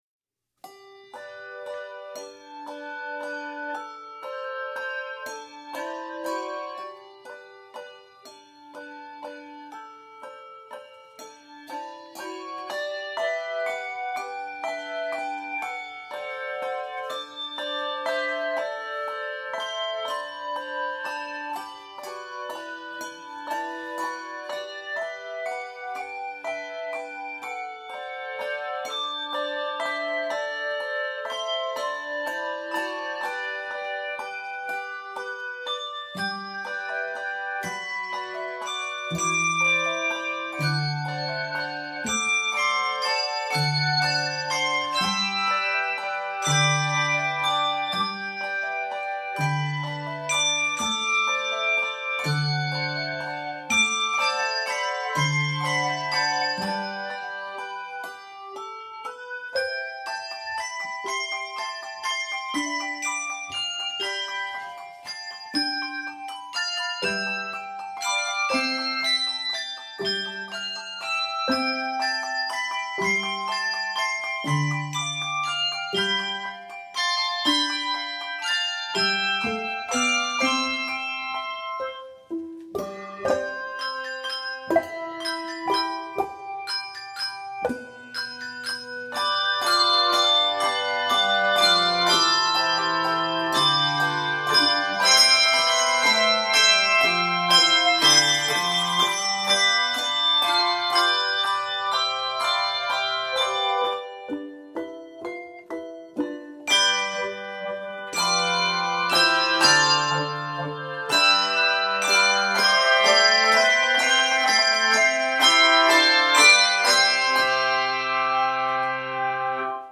children's hymn
Keys of C Major and G Major.